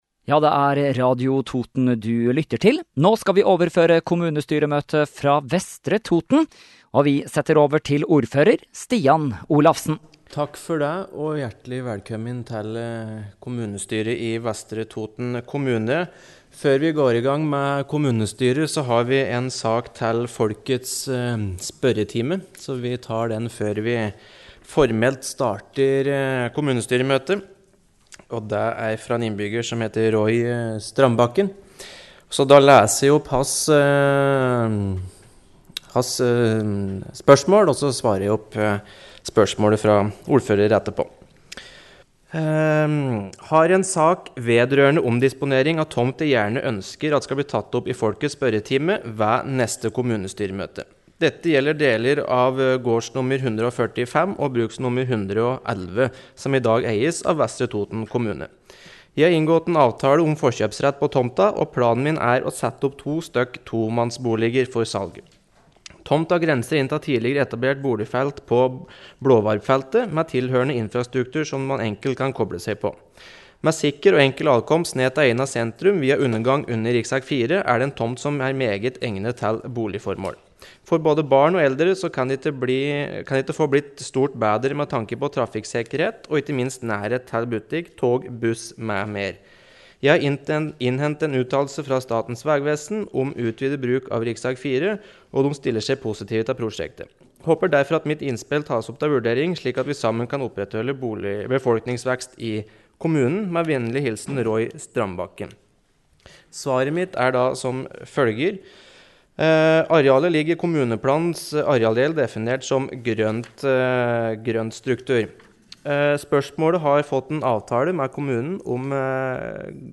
Kommunestyremøte fra Vestre Toten 27. august | Radio Toten